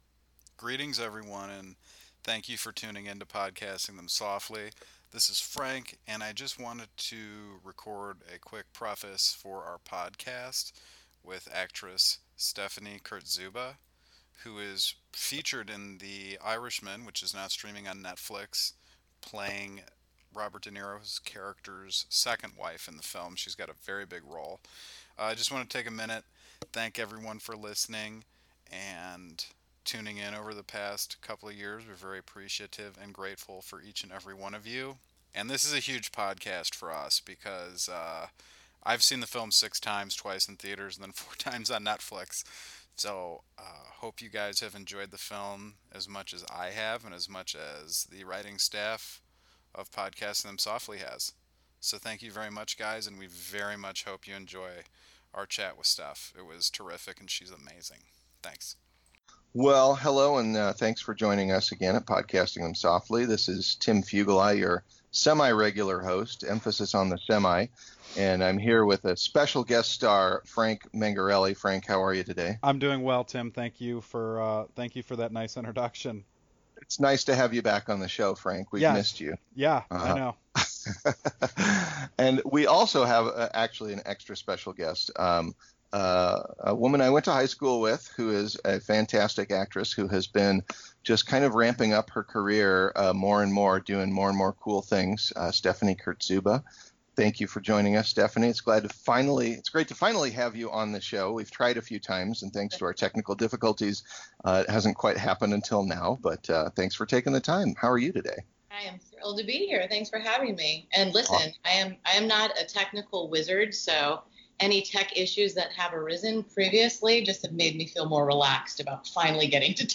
We are completely honored to bring you our chat